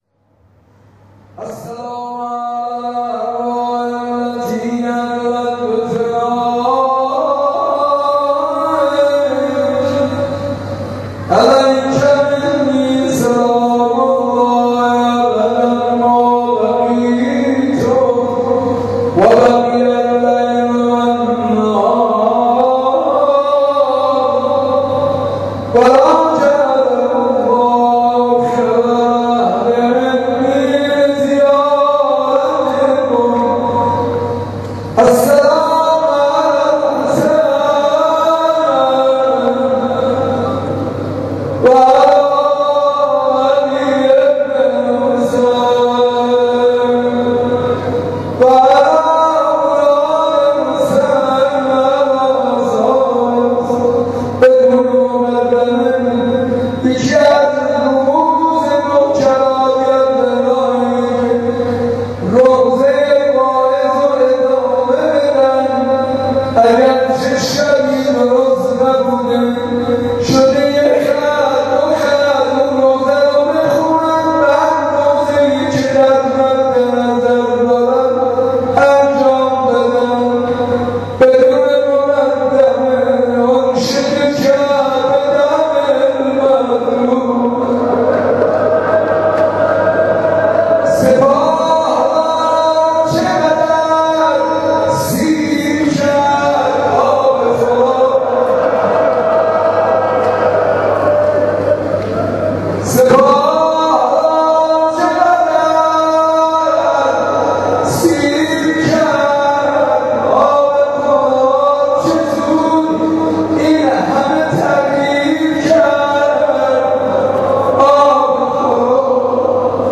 به گزارش عقیق، اجتماع بزرگ مداحان، شاعران و خادمان هیئات مذهبی در مسجد ارک تهران و با سخنرانی حجت الاسلام قاسمیان و حاج منصور ارضی و همچنین شعرخوانی شاعران اهل بیت علیهم السلام برگزار شد.
در پایان حاج منصور ارضی به روضه خوانی پرداخت که در ادامه صوت آن را می شنوید.
روضه خوانی حاج منصور ارضی در لحظات پایانی مراسم